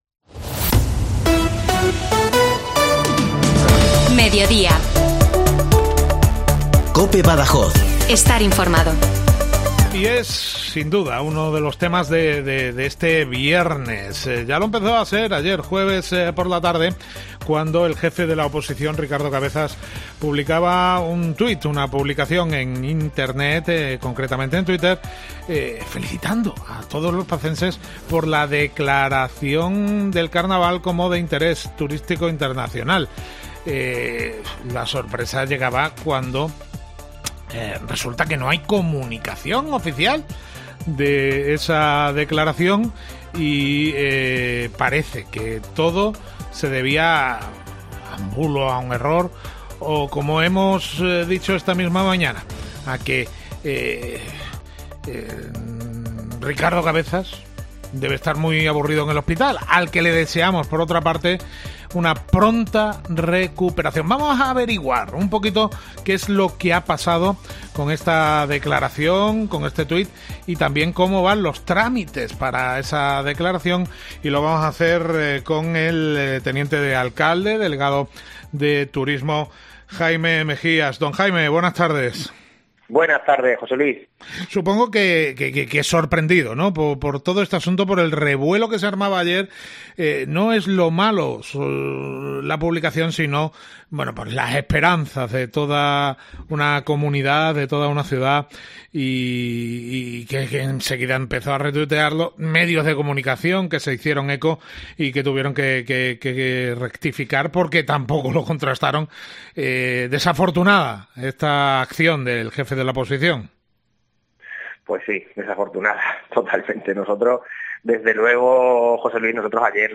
Hablamos con el concejal de Turismo sobre la Declaración del Carnaval como de Interés Turístico Internacional